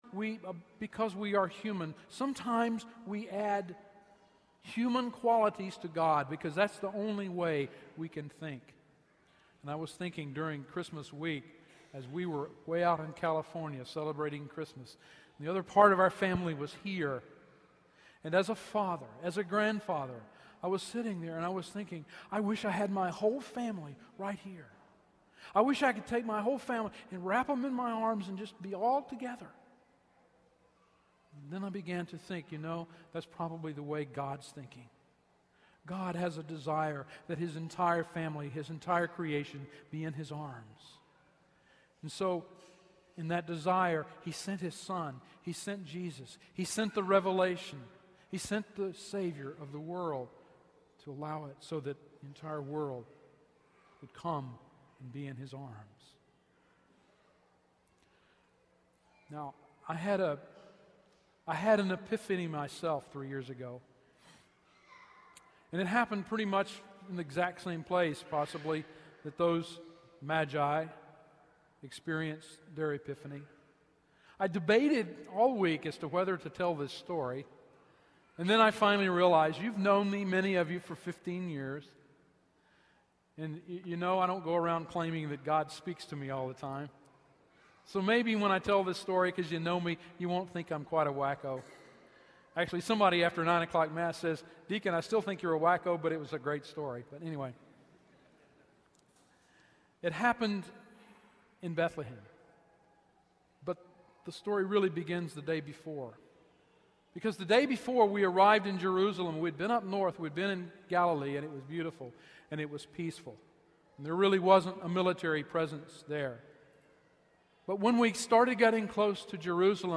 Epiphany 2009 Homily
Epiphany2009-Homily.mp3